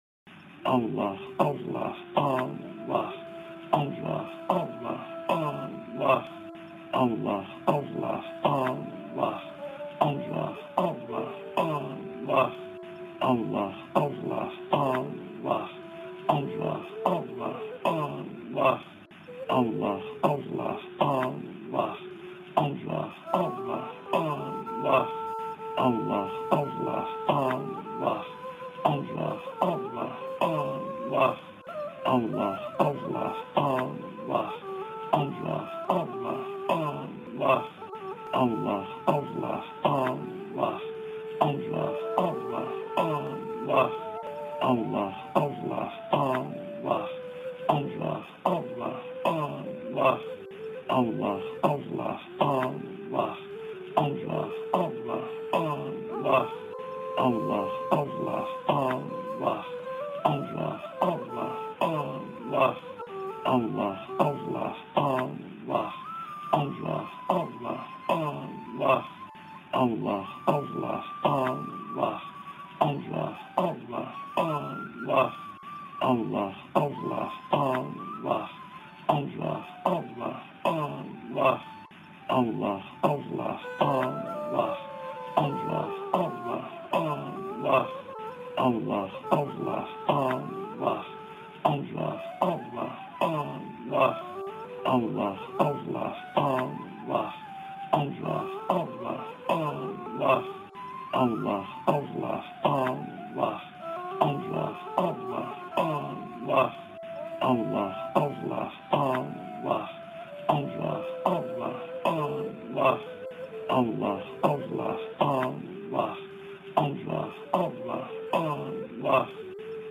zikir mp3
0cehri-zikir-1-saatlik.mp3